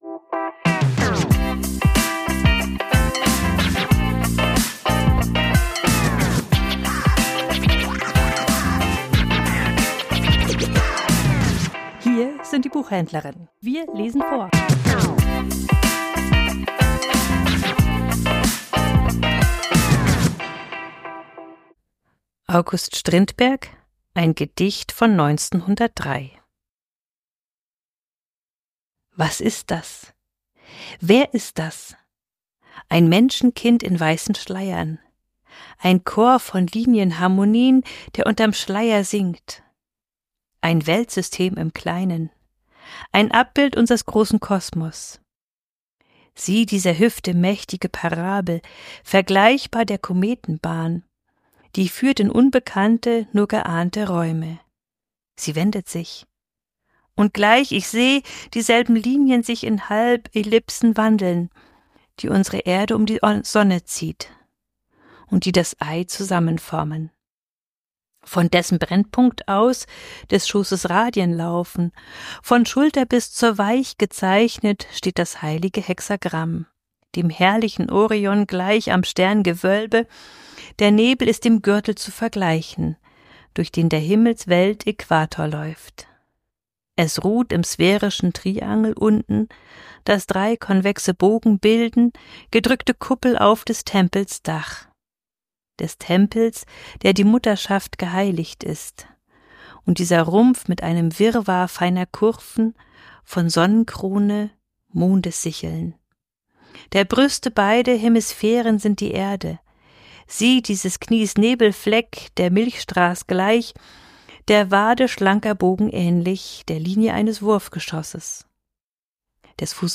Vorgelesen: Strindberg - Gedicht von 1903